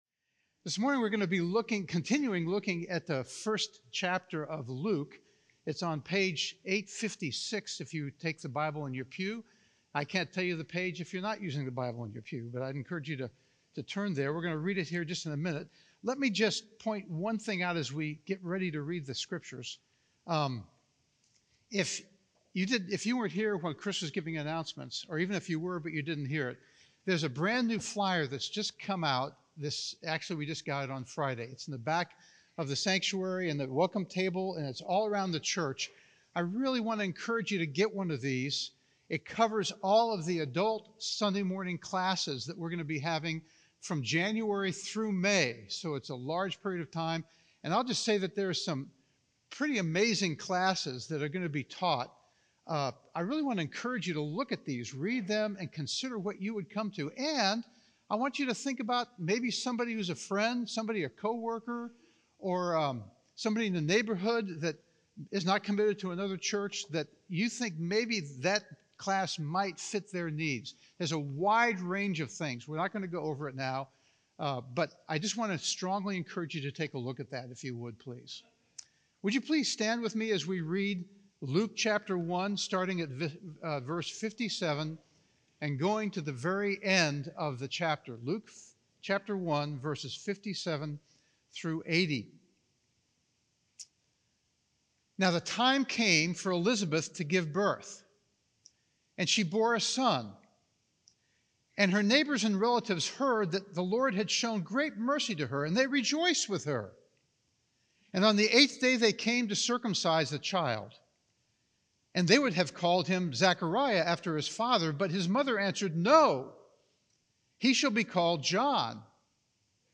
View this week’s sermon discussion questions .